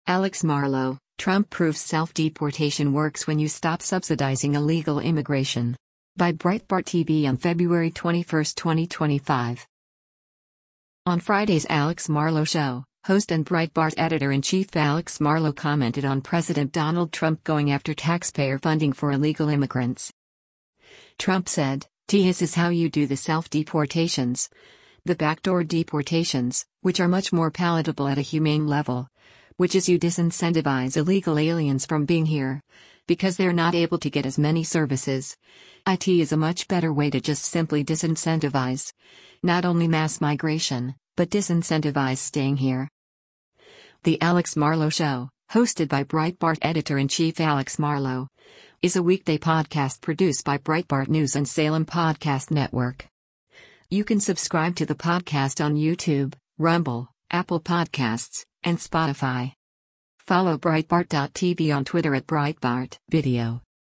On Friday’s “Alex Marlow Show,” host and Breitbart Editor-in-Chief Alex Marlow commented on President Donald Trump going after taxpayer funding for illegal immigrants.